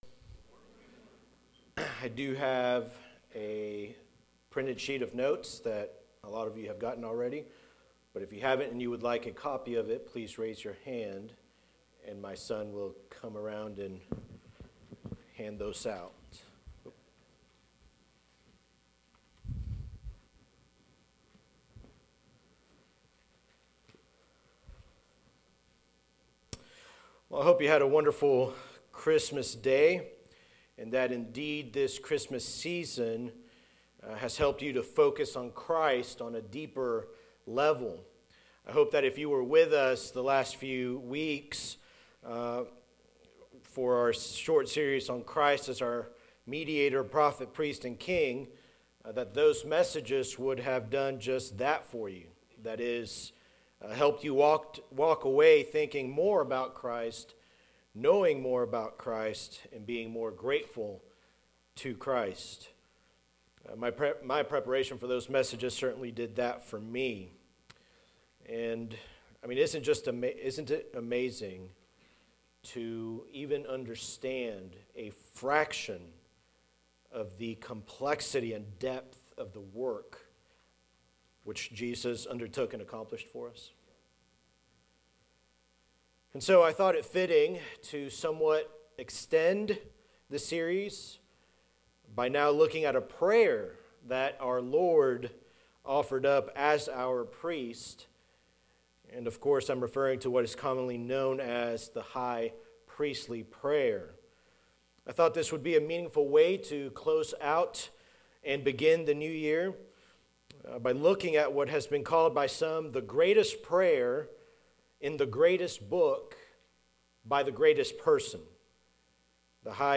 Sermons by Eatonville Baptist Church EBC